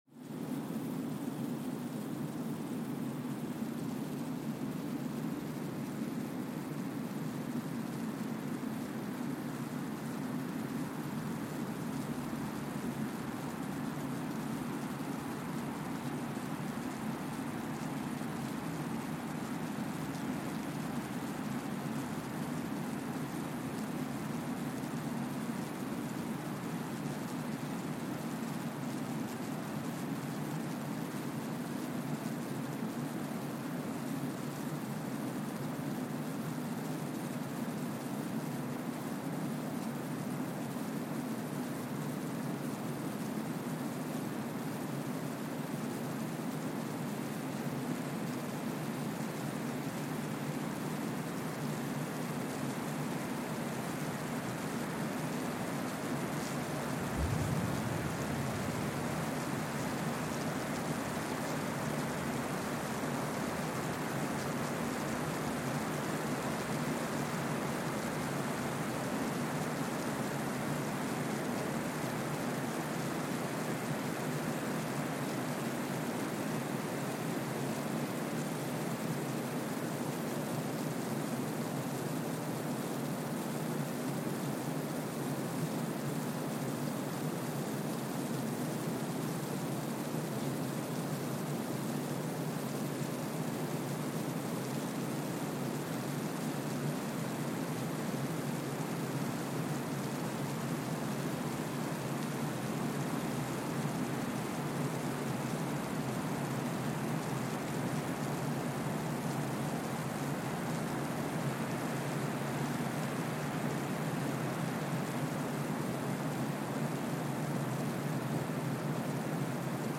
Kwajalein Atoll, Marshall Islands (seismic) archived on June 8, 2023
Sensor : Streckeisen STS-5A Seismometer
Speedup : ×1,000 (transposed up about 10 octaves)
Loop duration (audio) : 05:45 (stereo)